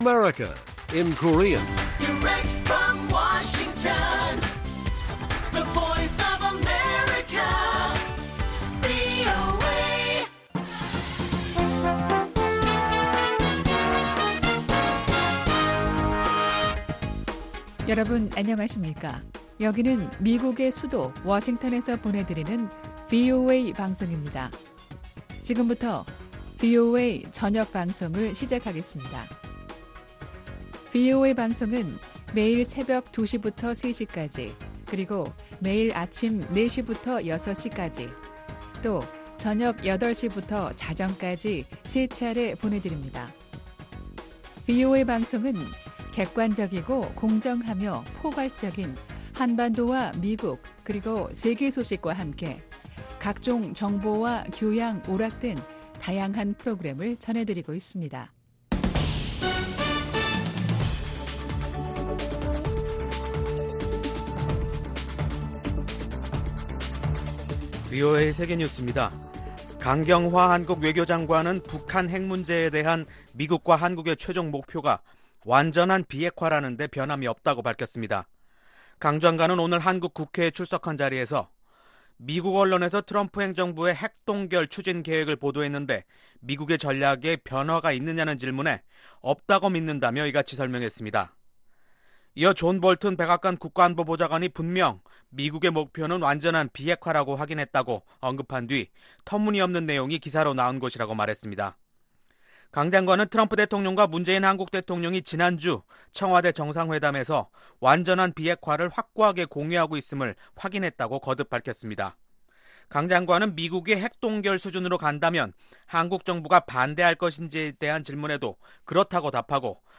VOA 한국어 간판 뉴스 프로그램 '뉴스 투데이', 2019년 7월 2일 1부 방송입니다. 한국의 강경화 외교장관은 북한의 완전한 비핵화가 한국 정부의 입장이라고 밝혔습니다. 미국의 전문가들은 북핵 동결은 완전한 비핵화를 위한 첫 단계일 뿐, 핵 시설과 무기의 폐기와 함께 검증이 뒤따라야 한다고 말하고 있습니다.